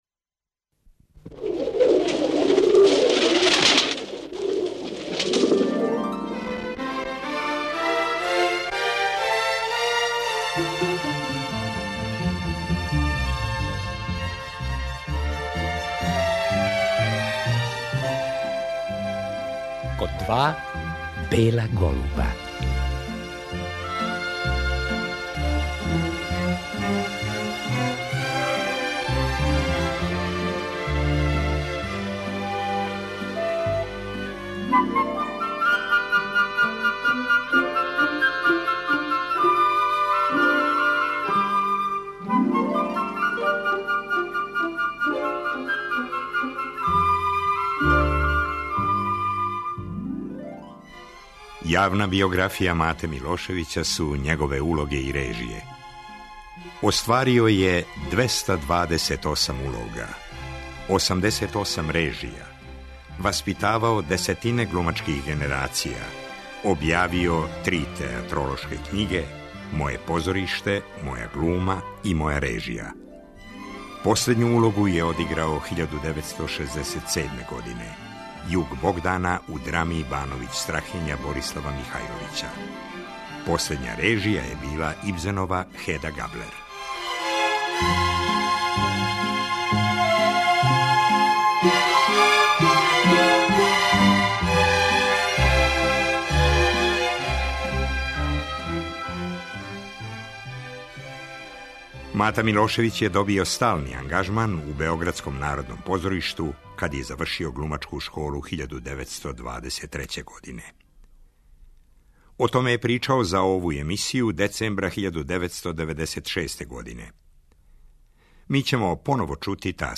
Гост ове емисије професор Милошевић је био у десембру 1996. године. У разговору је учестовала његова ученица, глумица Босиљка Боци.